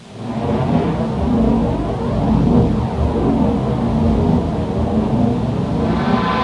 Synthetic Thunder Sound Effect
Download a high-quality synthetic thunder sound effect.
synthetic-thunder.mp3